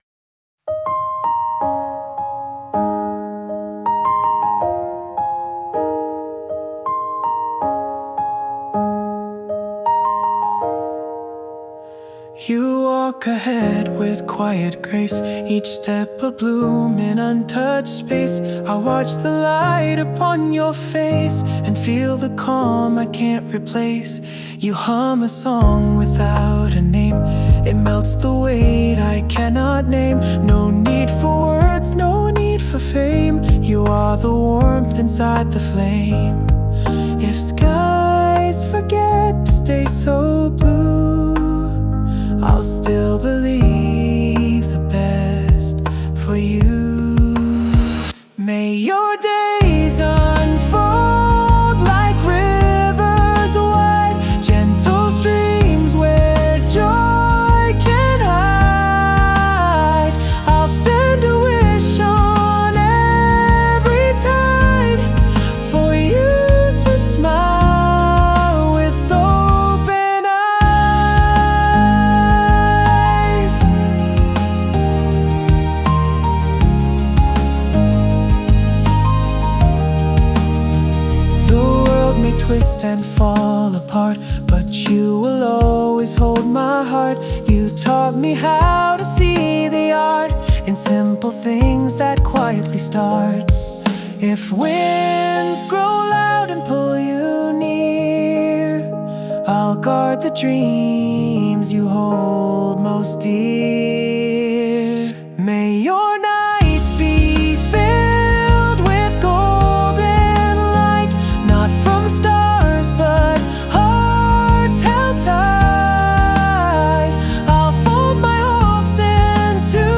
洋楽男性ボーカル著作権フリーBGM ボーカル
著作権フリーオリジナルBGMです。
男性ボーカル（洋楽・英語）曲です。
派手な展開や強いメッセージ性よりも、静かにじわじわと染み込んでいくような曲